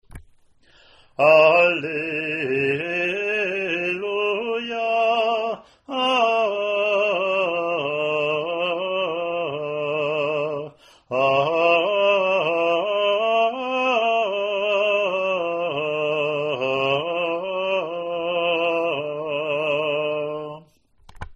Year A Alleluia Acclamation
ot32bc-alleluia-gm.mp3